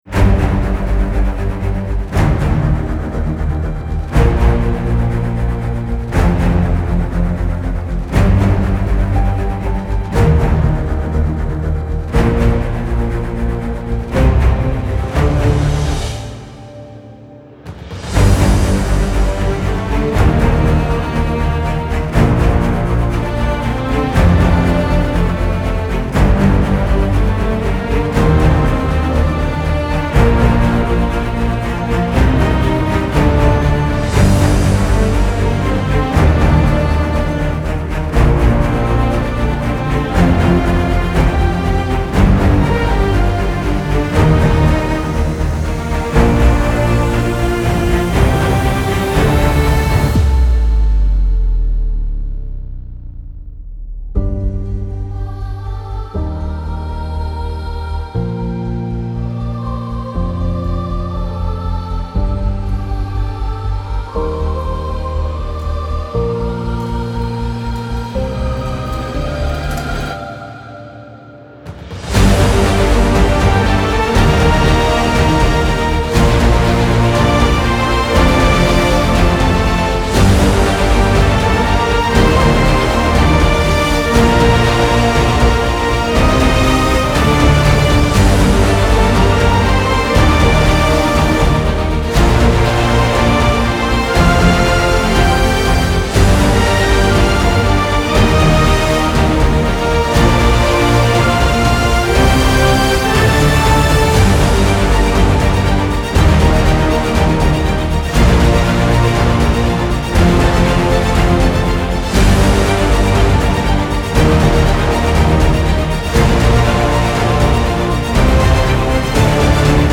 سبک اپیک , موسیقی بی کلام
موسیقی بی کلام ارکسترال